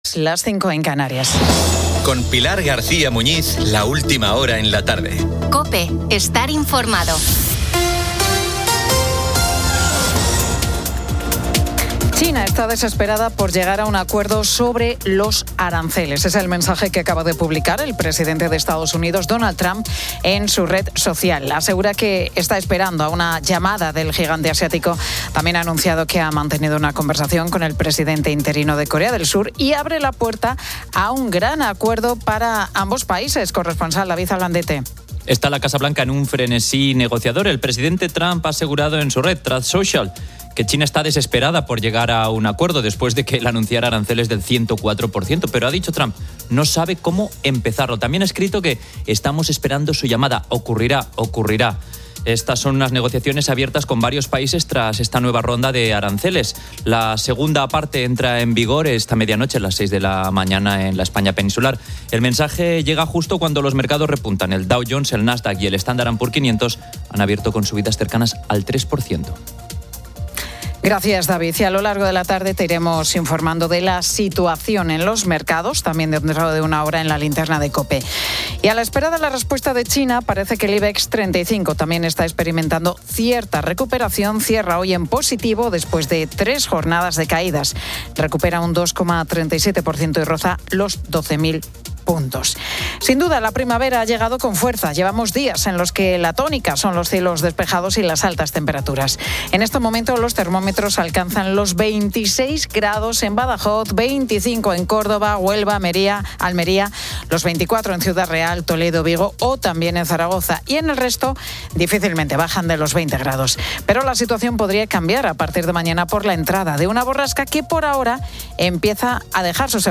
La Tarde 18:00H | 08 ABR 2025 | La Tarde Pilar García Muñiz se pregunta cuántos coches se roban en España y conecta con Asturias para conocer cómo ha ido la que es la primera investigación en la mina de Cerredo. También entrevista a Rayden, músico, escritor y poeta.